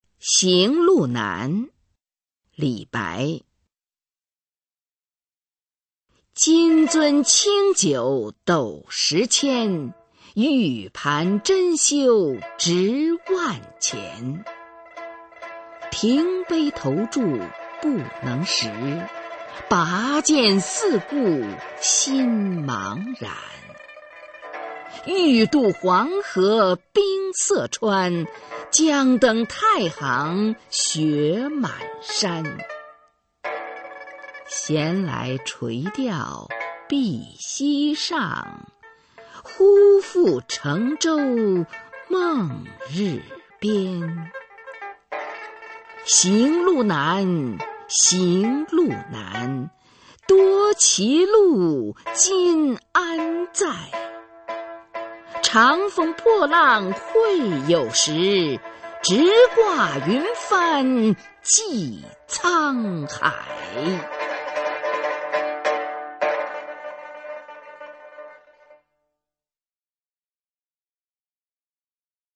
[隋唐诗词诵读]李白-行路难（女） 唐诗吟诵